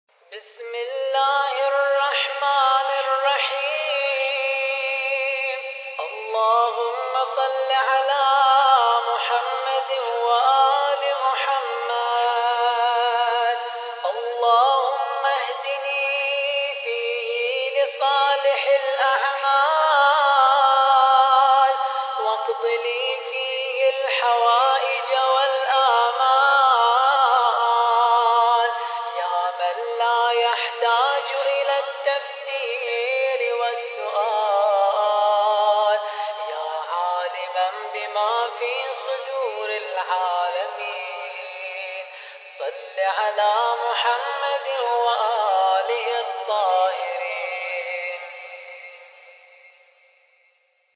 الخطیب: الرادود